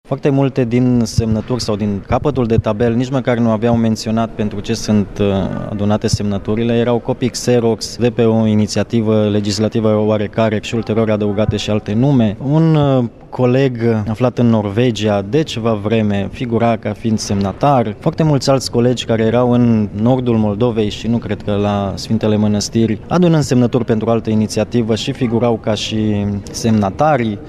Lista de semnături care a stat la baza solicitării Opoziţiei privind organizarea, pe timpul vacanţei parlamentare, a unei sesiuni extraordinare a Legislativului în legătură cu violenţele de la protestul din 10 august, este subiectul unei plângeri penale anunţate de PSD. Liderul deputaţilor social democraţi, Daniel Suciu spune că este vorba despre fals în înscrisuri publice.